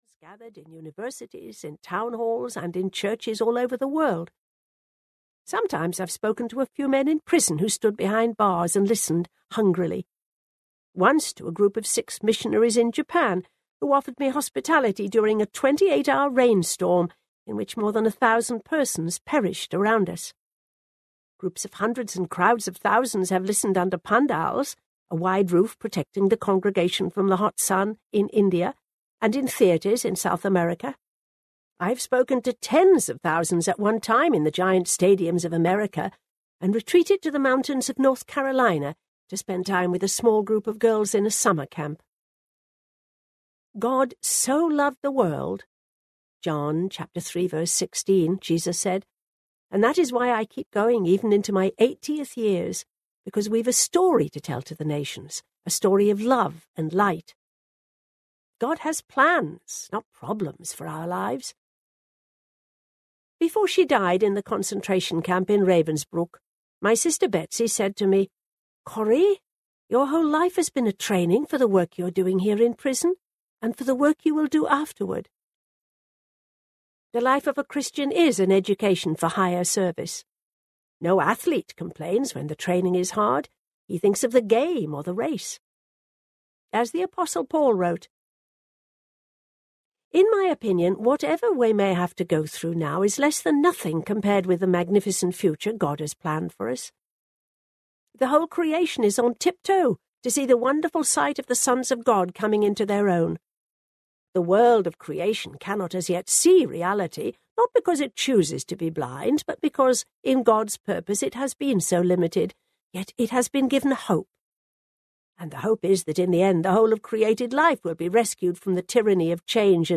Tramp for the Lord Audiobook
5.5 Hrs. – Unabridged